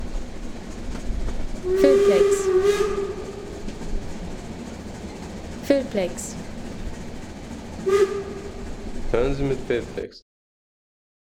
Pfeifsignale der Dampflok
Die Signaltöne der Dampflokomotive.